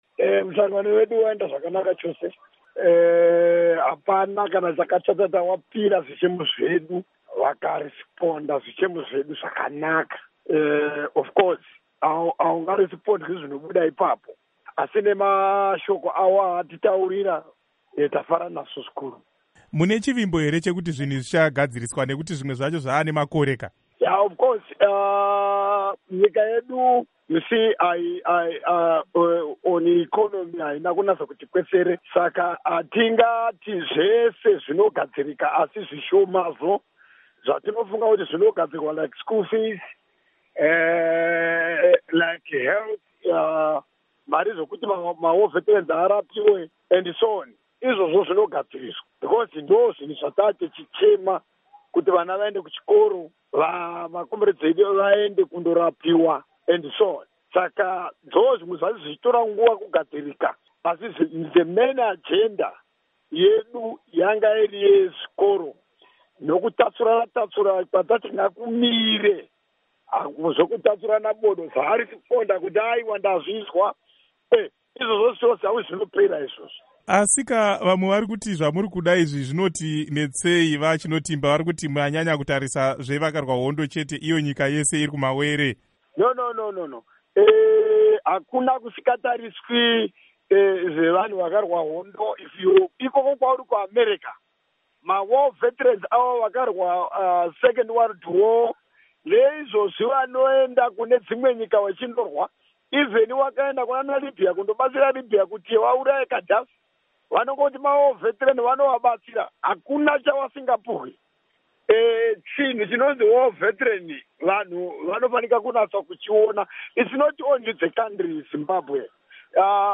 Hurukuro naVaJoseph Chinotimba